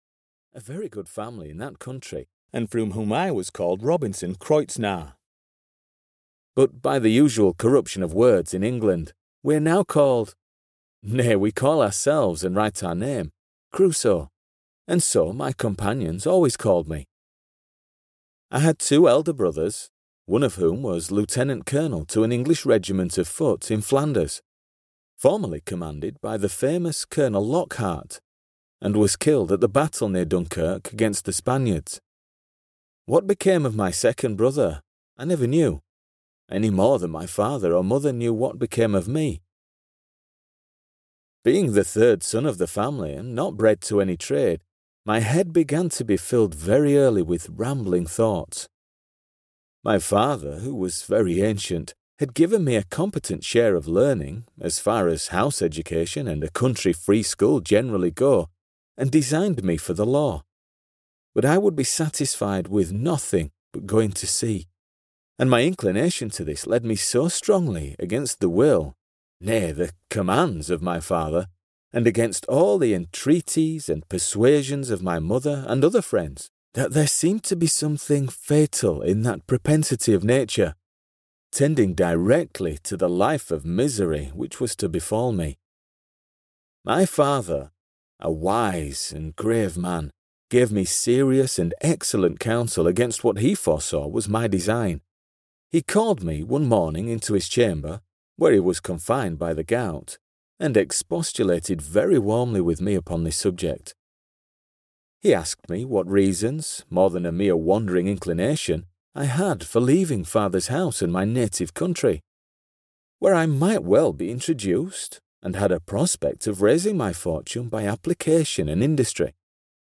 Robinson Crusoe (EN) audiokniha
Ukázka z knihy